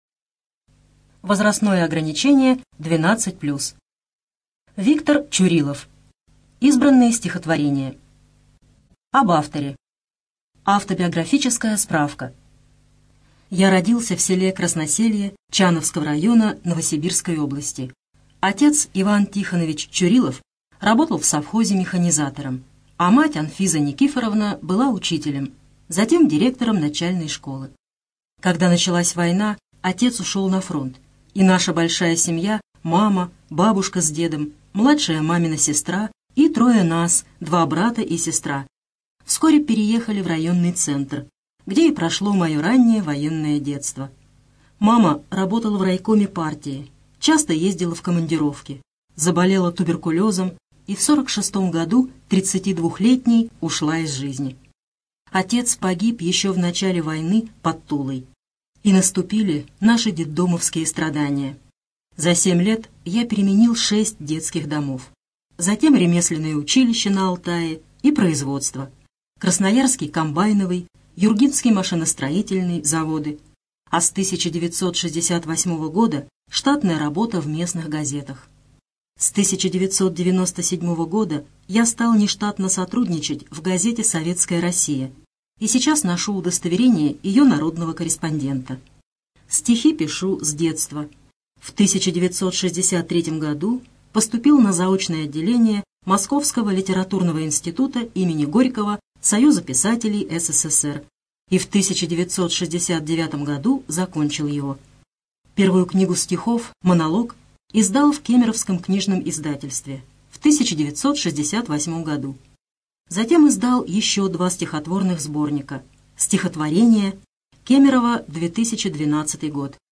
ЧитаетАвтор
ЖанрПоэзия
Студия звукозаписиКемеровская областная специальная библиотека для незрячих и слабовидящих